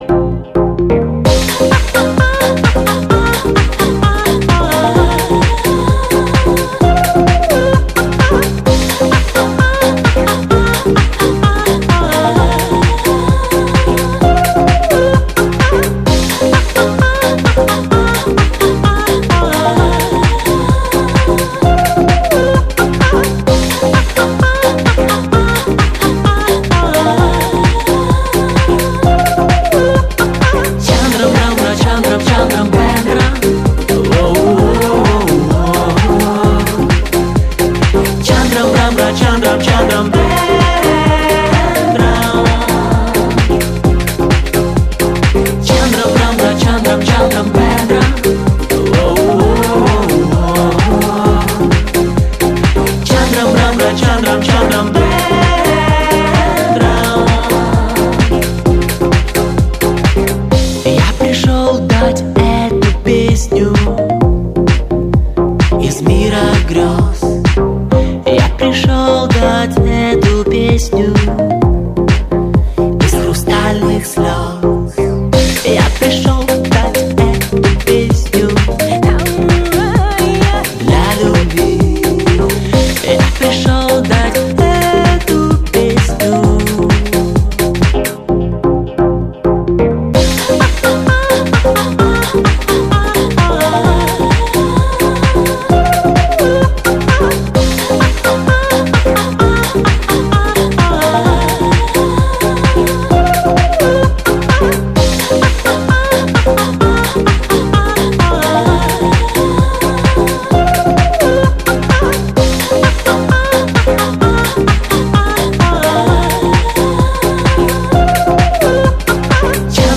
поп
забавные
веселые